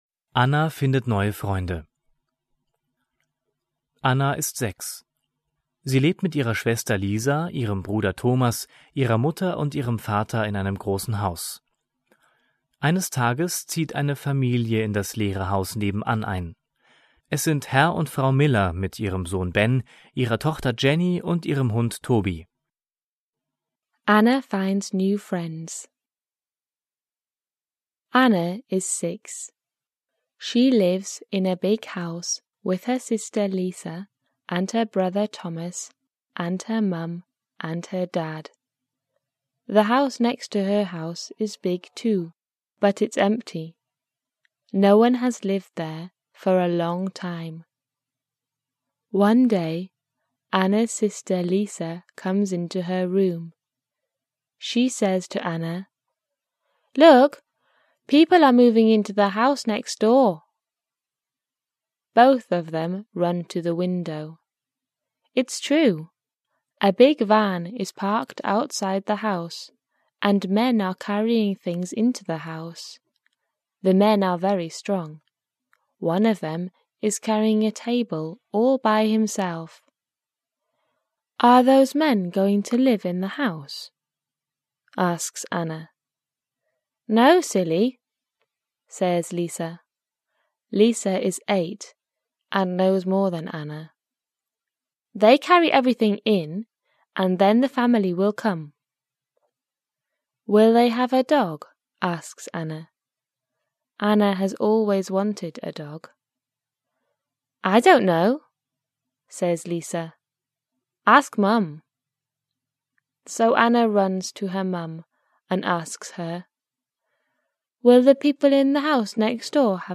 Vor jeder englischen Geschichte ist eine kurze Einführung auf Deutsch gegeben, die den Inhalt der Geschichte kurz zusammenfasst. Alle Geschichten sind von englischen Muttersprachlern gesprochen, um den Kindern ein Gefühl für die Sprachmelodie und Aussprache zu vermitteln.